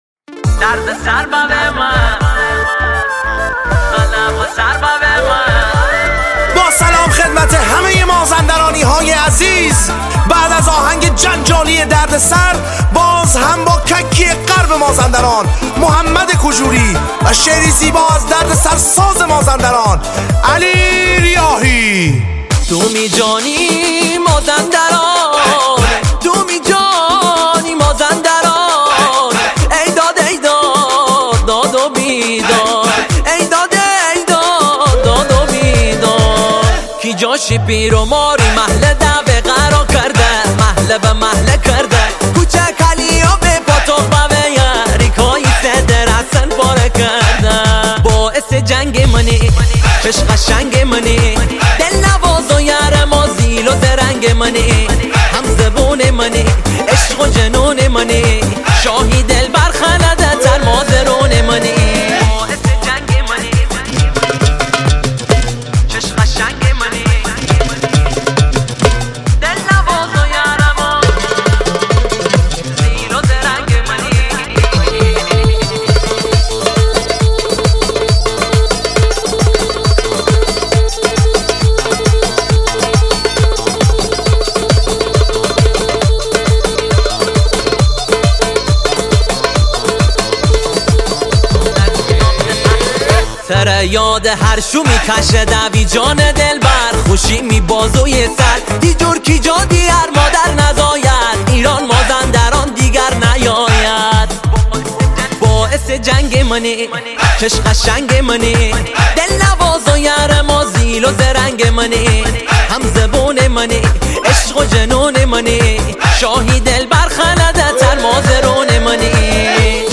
ریمیکس شاد تند بیس دار سیستمی